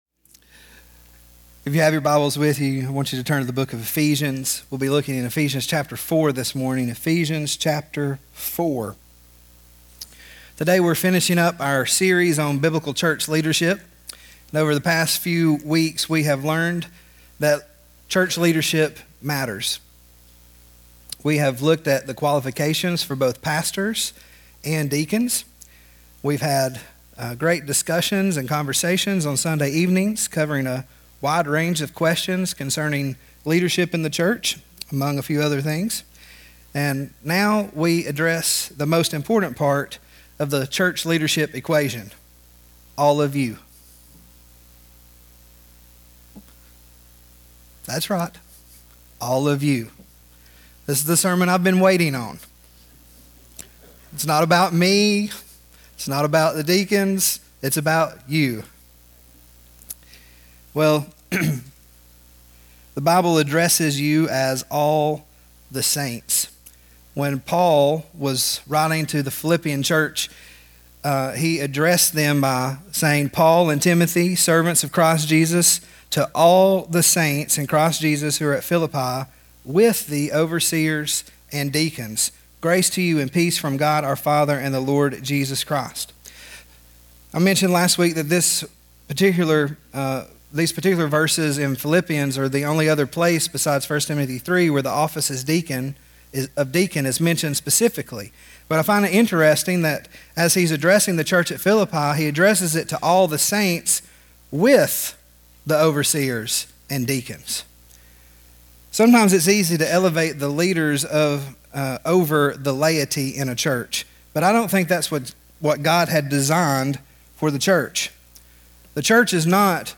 Ephesians 4:11-16 Service Type: AM Worship We need each other to fulfill the purpose that God has for His church.